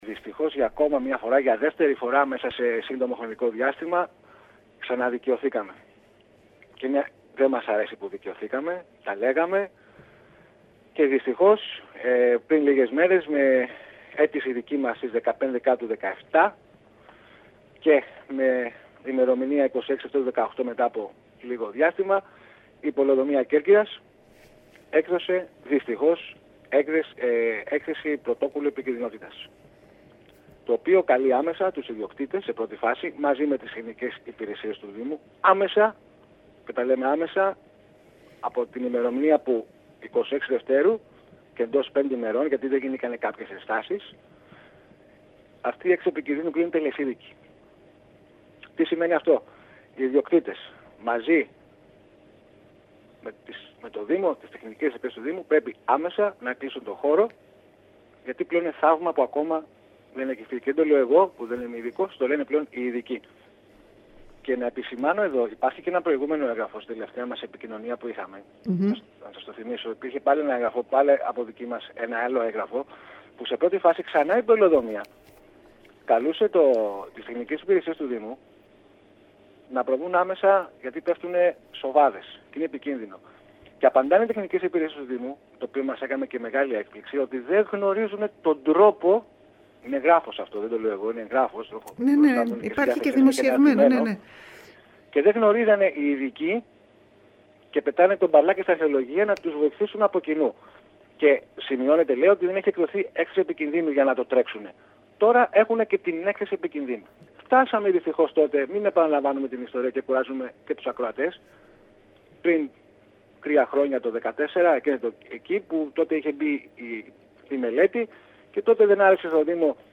Πρωτόκολλο επικινδύνου συνέταξε η πολεοδομία του δήμου αναφορικά με το μνημείο της Ανουτσιάτα εντός του  ιστορικού κέντρου της πόλης. Μιλώντας στην ΕΡΤ Κέρκυρας